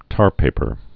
(tärpāpər)